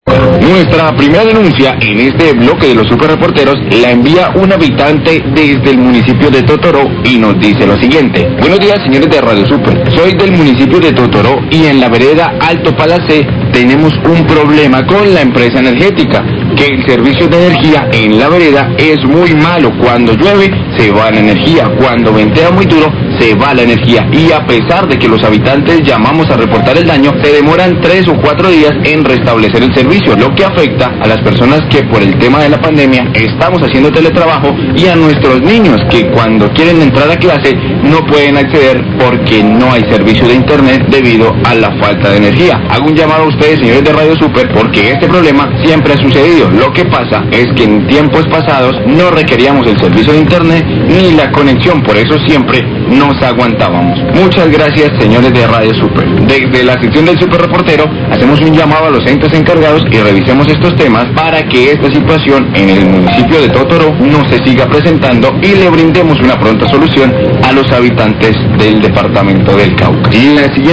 Radio
Habitante de Totoró en la vereda Alto Palacé denuncia que tienen problemas con el servicio de energía, es muy malo, llaman y se demoran de 3 a 4 días en restablecer el servicio, esto afecta a los teletrabajadores y a los niños que tienen que acceder a clase por internet.